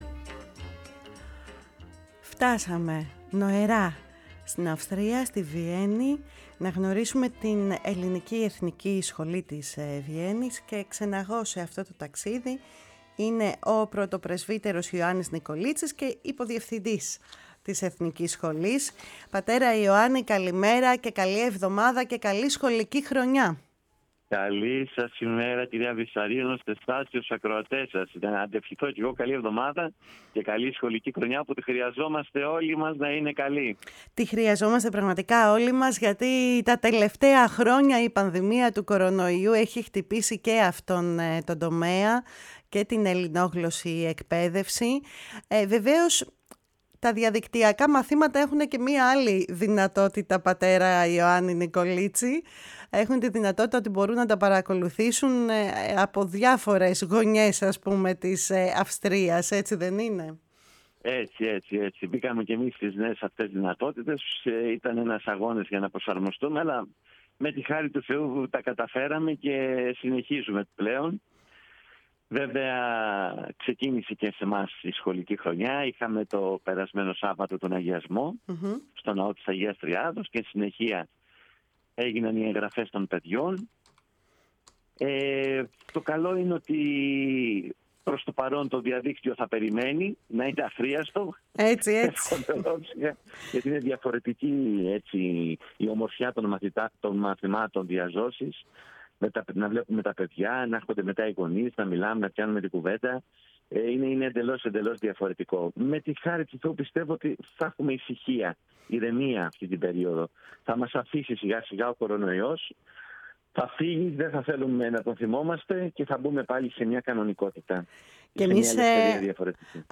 μίλησε στη “Φωνή της Ελλάδας”, στην εκπομπή “Κουβέντες Μακρινές”